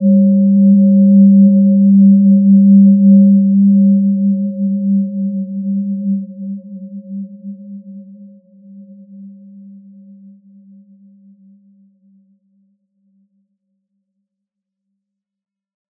Gentle-Metallic-4-G3-p.wav